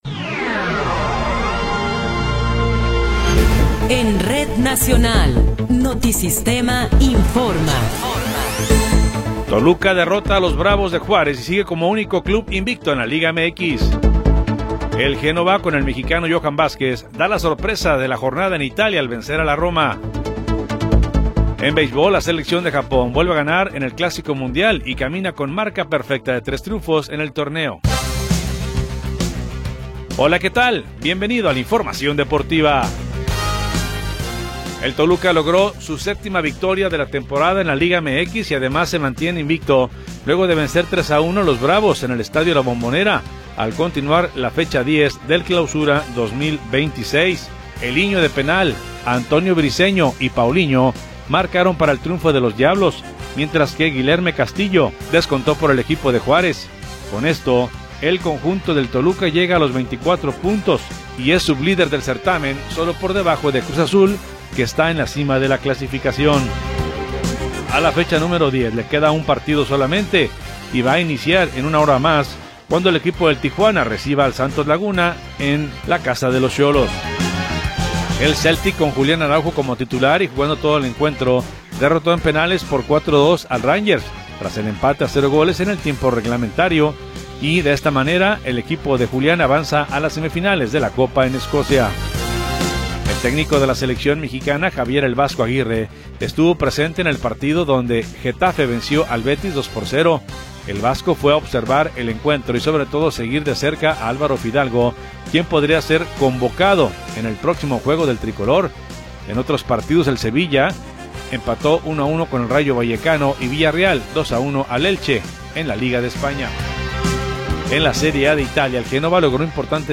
Noticiero 20 hrs. – 8 de Marzo de 2026
Resumen informativo Notisistema, la mejor y más completa información cada hora en la hora.